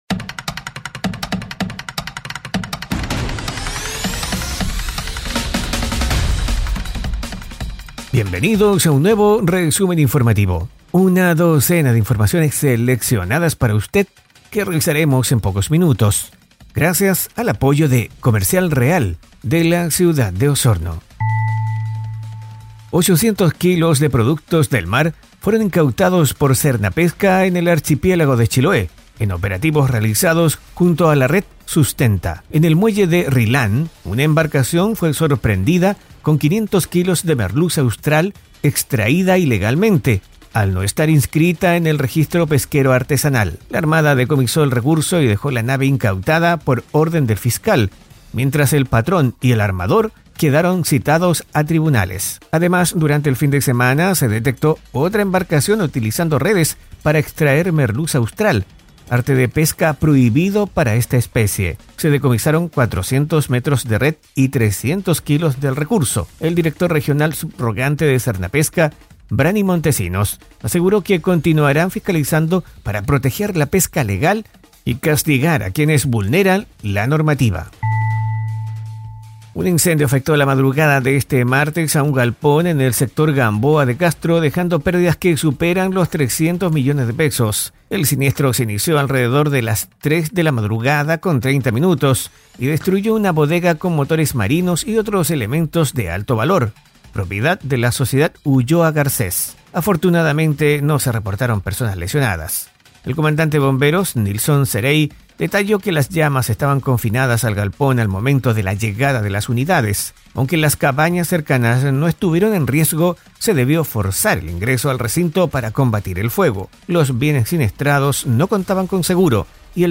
🎙 ¡Tu resumen informativo en podcast está aquí! 🗞 🚀 Conoce las noticias más relevantes de la Región de Los Lagos de forma ágil y breve.